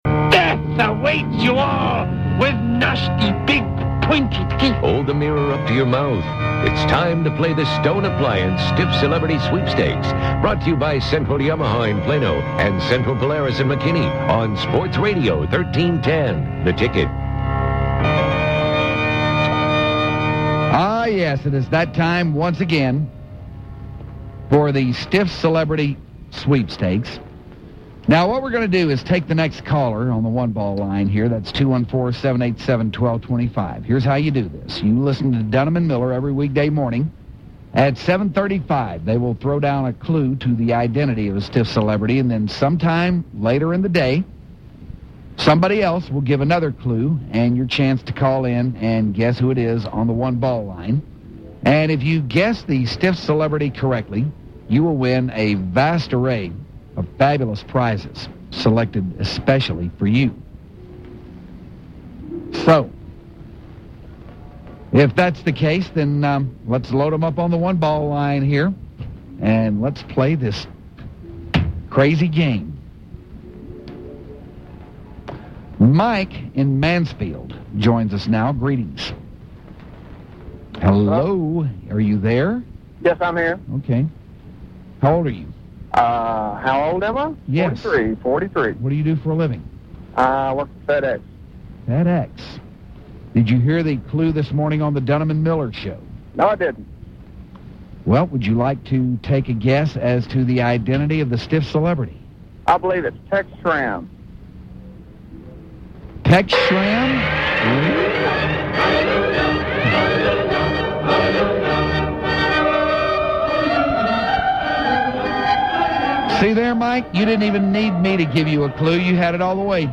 The Harliners have 2 members of Dallas Rock Band Olospo in studio to help out with the Concert Calender